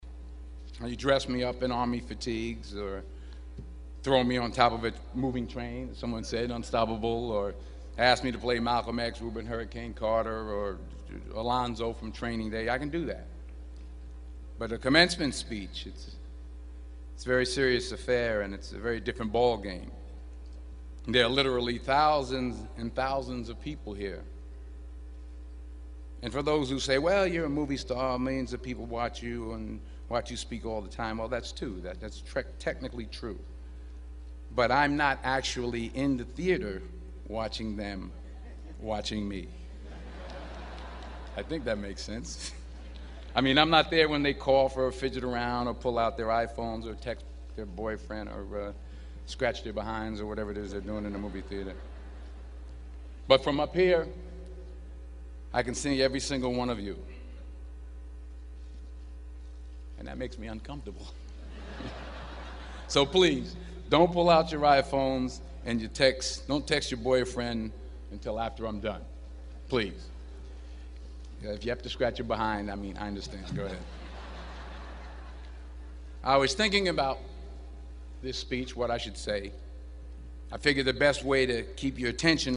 公众人物毕业演讲第419期:丹泽尔2011宾夕法尼亚大学(3) 听力文件下载—在线英语听力室